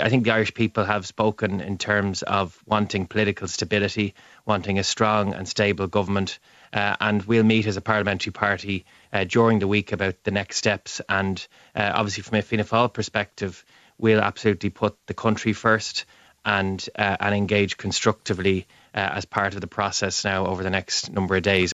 Finance Minister and Fianna Fail’s Director of Elections Jack Chambers, says his party will discuss the next steps in the coming days……………